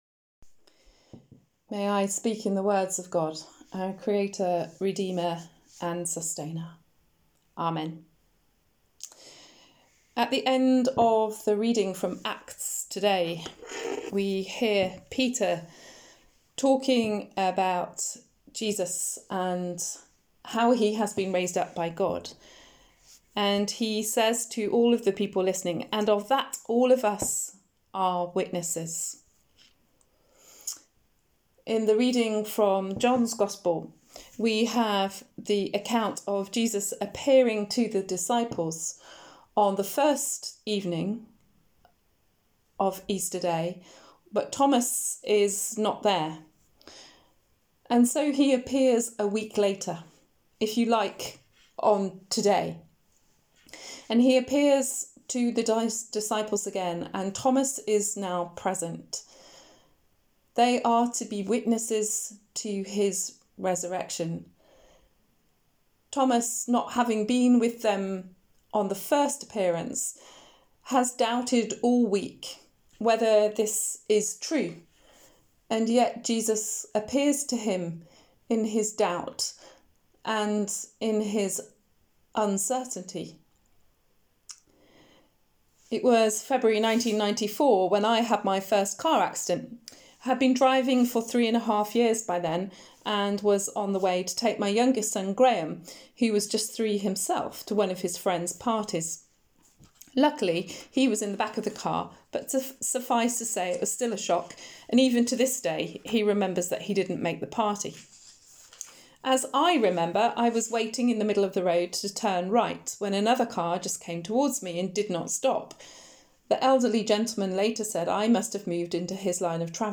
Sermon: You Are Witnesses – But What to? | St Paul + St Stephen Gloucester